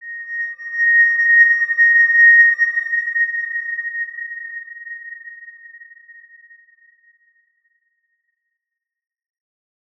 X_Windwistle-A#5-ff.wav